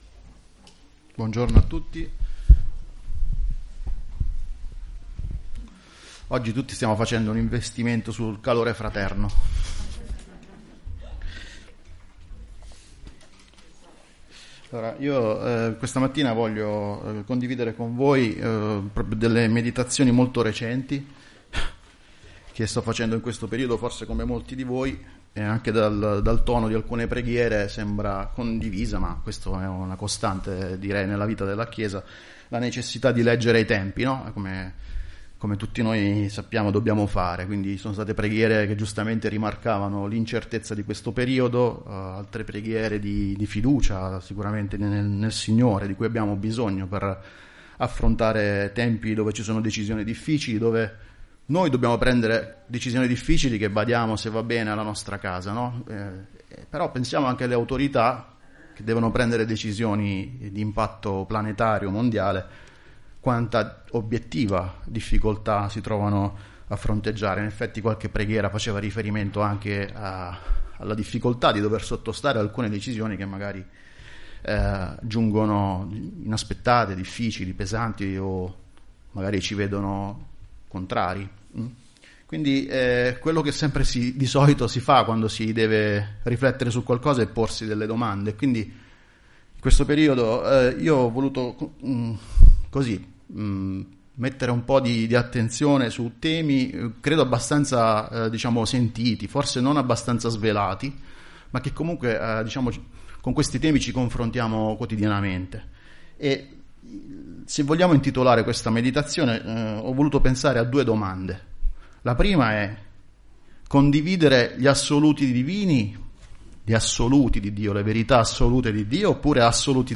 Chiesa Cristiana Evangelica - Via Di Vittorio, 14 Modena
Predicazioni